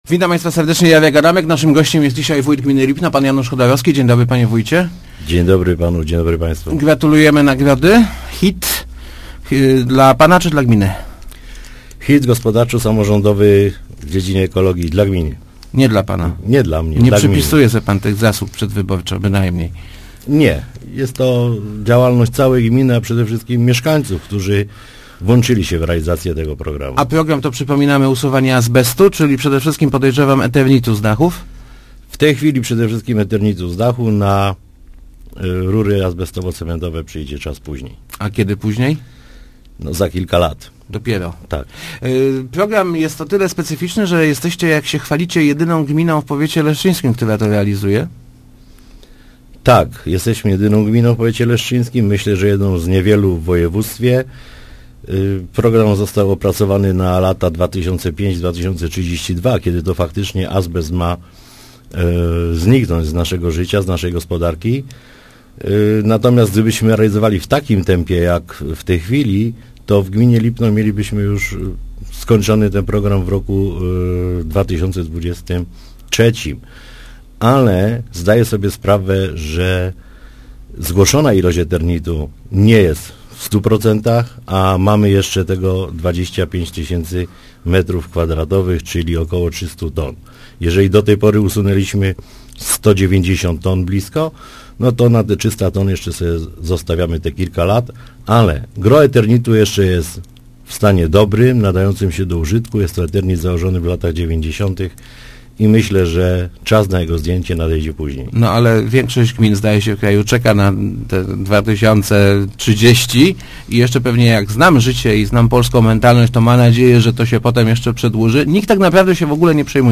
chodorowski80.jpgBudowa boiska „Orlik” zaplanowana jest na 2011 rok – mówił w Rozmowach Elki wójt gminy Lipno Janusz Chodorowski. Priorytetem gminy jest budowa sieci kanalizacyjnej, dopiero potem przyjdzie czas na inne inwestycje. Tak więc na budowę dróg w Wilkowicach trzeba będzie zaczekać aż do 2014 roku.